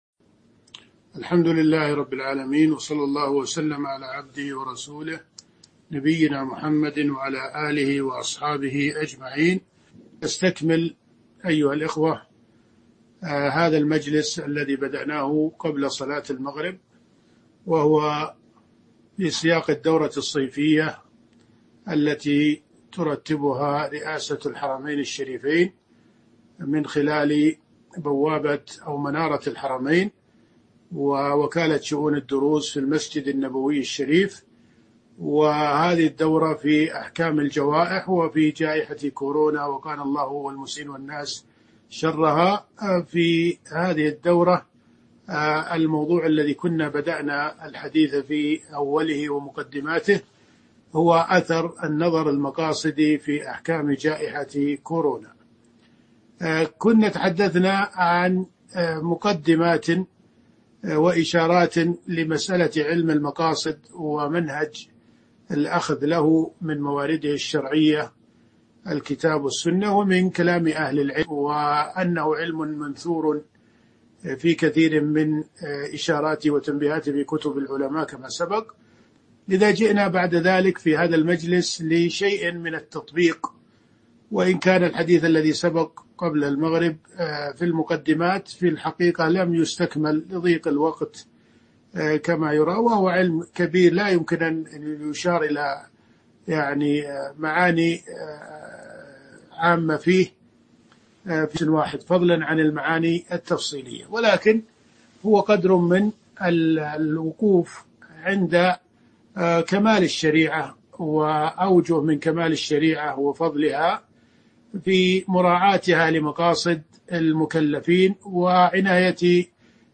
تاريخ النشر ٤ ذو القعدة ١٤٤١ هـ المكان: المسجد النبوي الشيخ